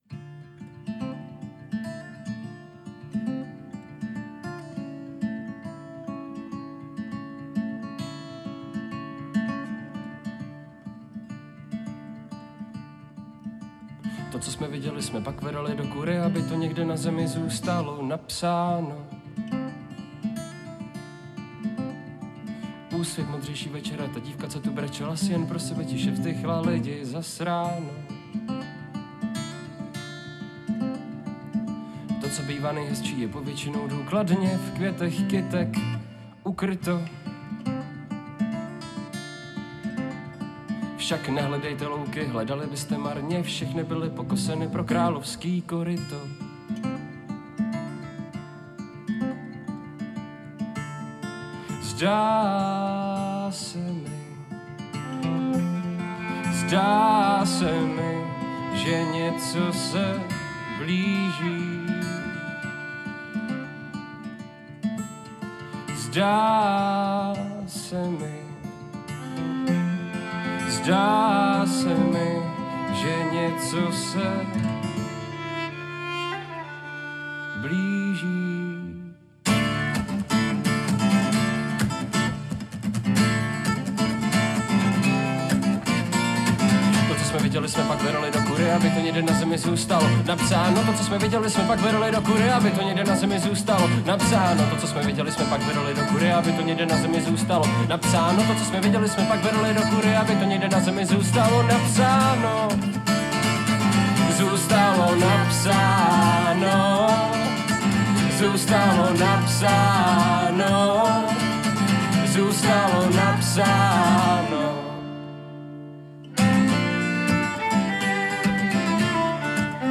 houslistka
kytarista